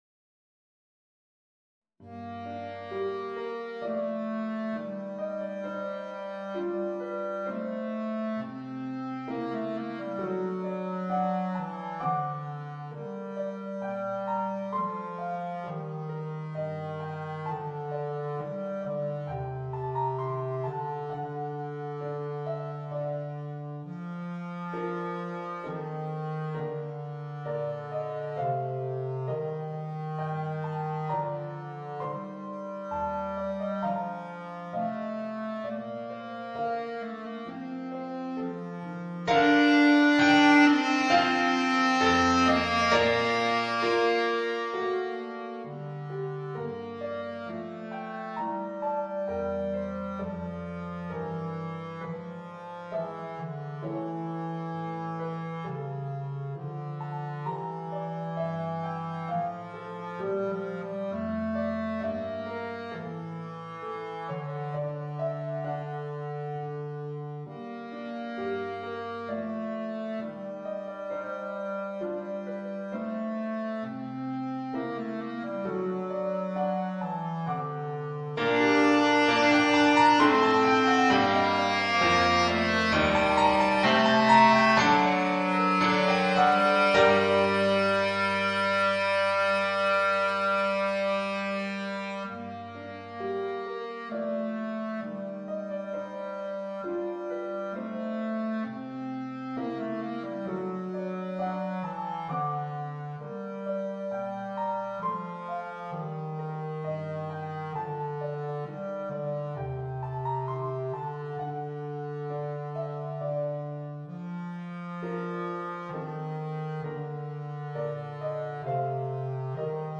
Voicing: Bass Clarinet and Organ